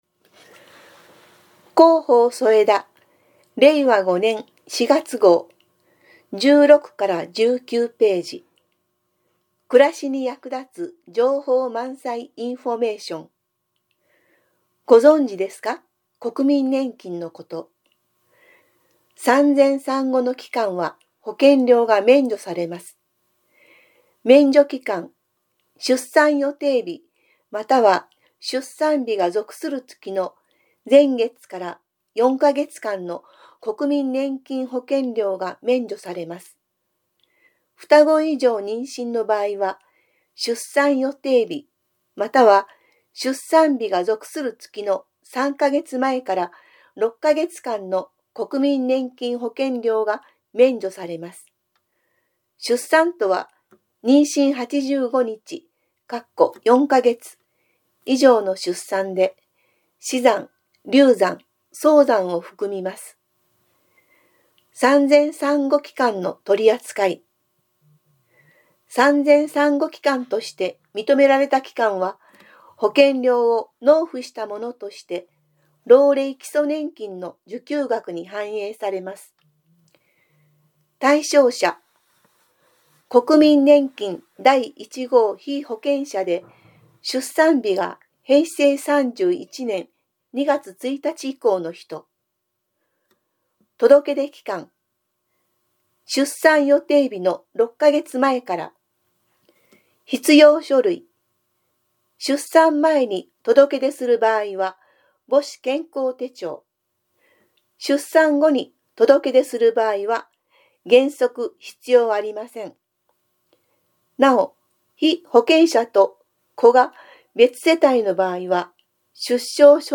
目や手の障がいなどにより、広報そえだを読むことができない人に広報紙の内容をお伝えするため、広報そえだを音声化しました。